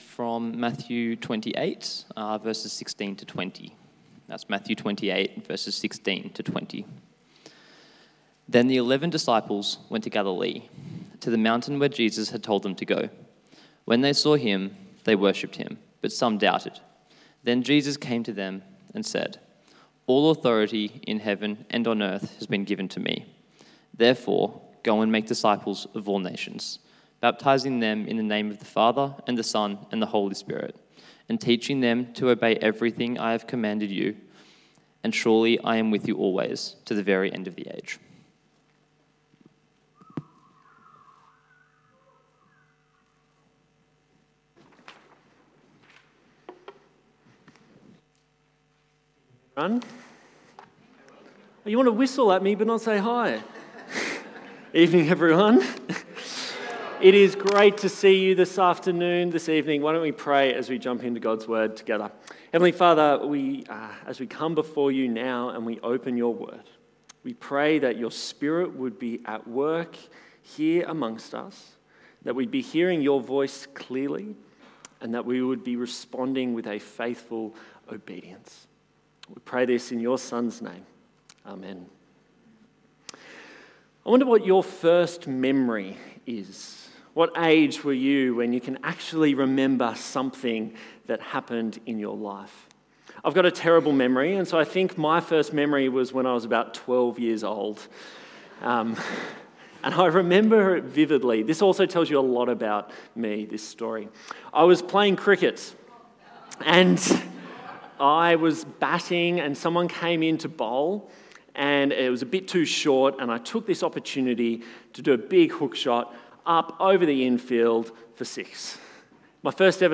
Service Type: 6PM